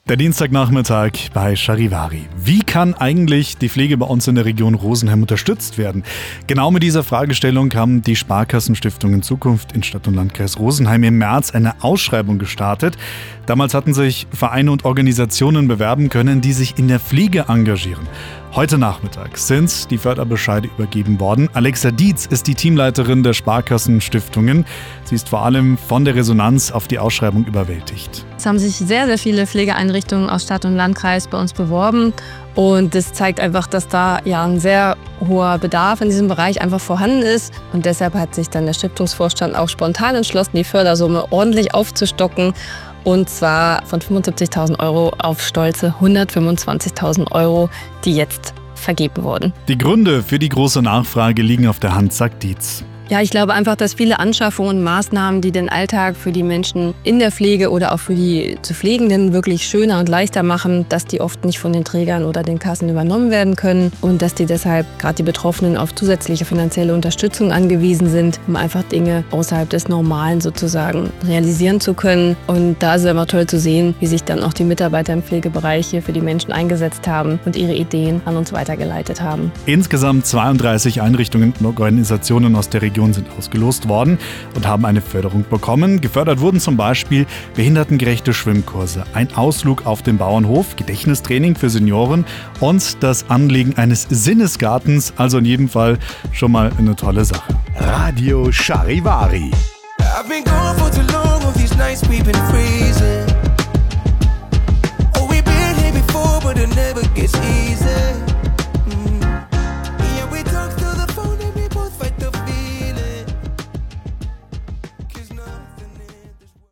Weitere Eindrücke zur Ausschreibung finden Sie im rfo-Beitrag oder im Interview mit dem Radio Charivari: